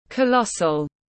Vô cùng lớn tiếng anh gọi là colossal, phiên âm tiếng anh đọc là /kəˈlɒs.əl/.
Colossal /kəˈlɒs.əl/